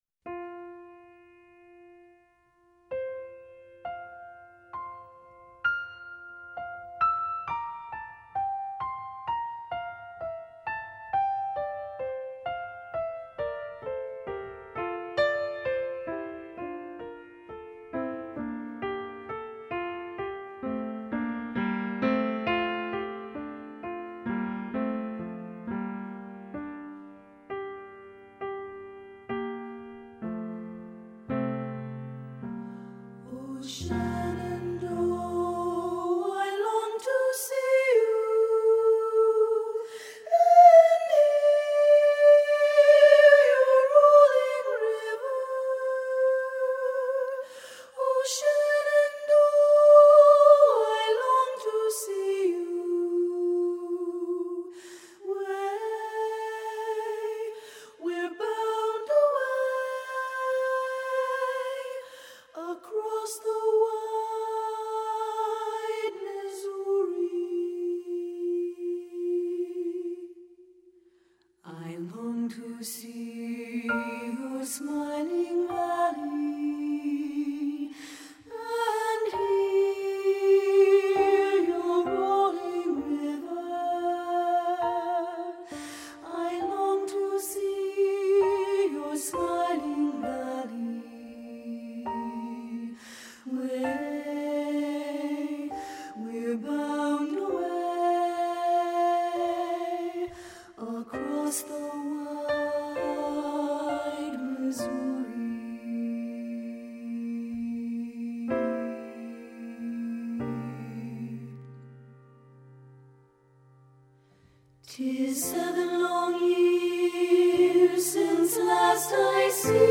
lawson gould choral
SSAA, sample